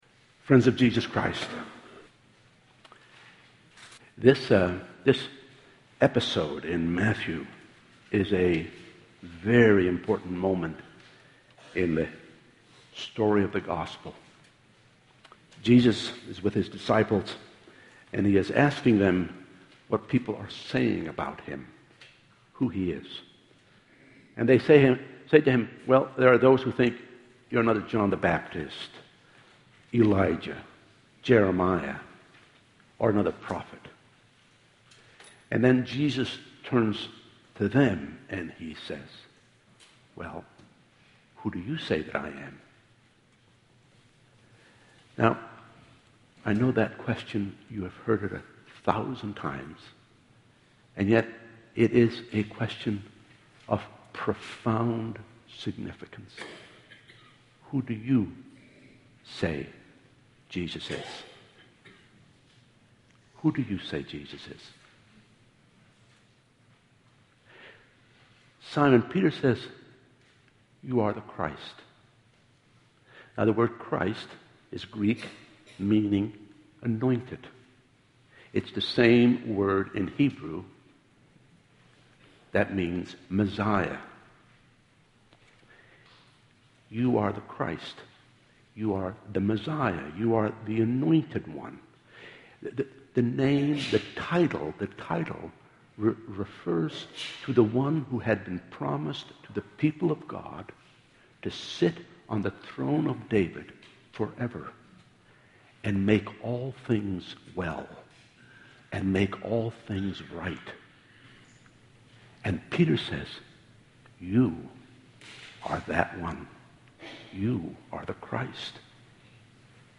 2013 Sermons I Will Build My Church Play Episode Pause Episode Mute/Unmute Episode Rewind 10 Seconds 1x Fast Forward 30 seconds 00:00 / Subscribe Share RSS Feed Share Link Embed Download file | Play in new window